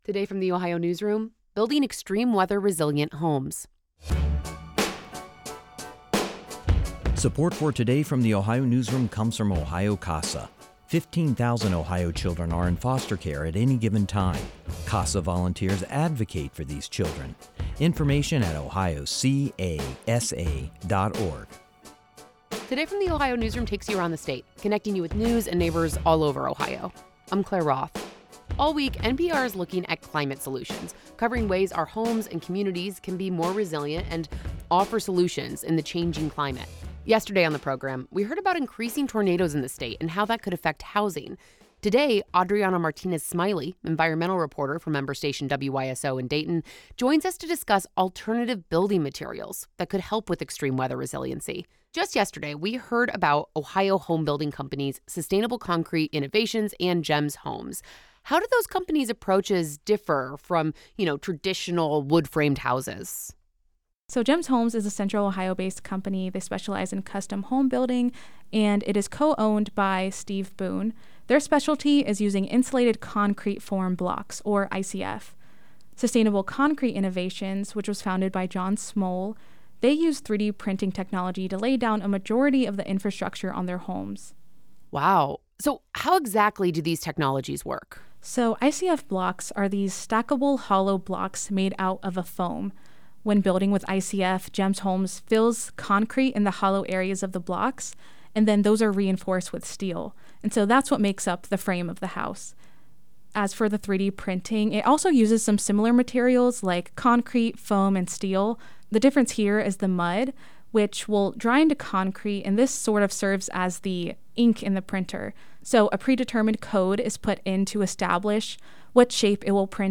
This conversation has been edited for clarity and brevity.